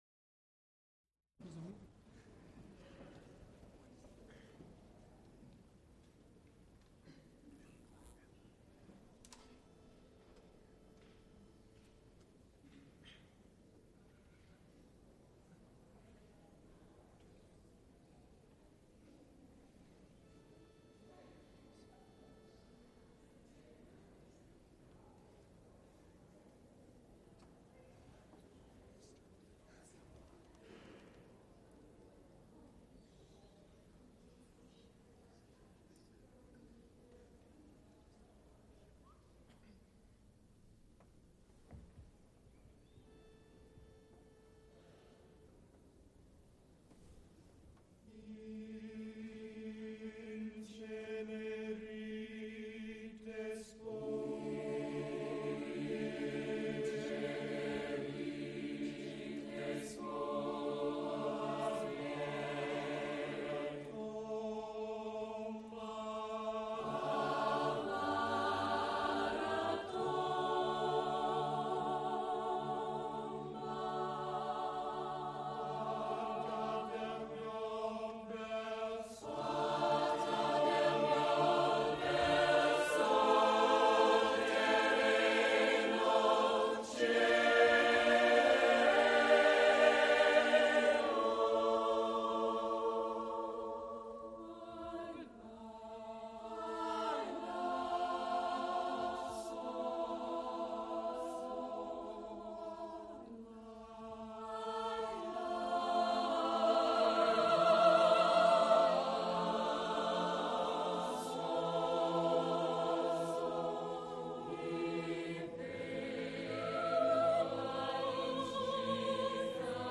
Recorded live April 8, 1979
sound recording-musical
Motets Madrigals, Italian Cantatas
Choruses, Secular (Mixed voices), Unaccompanied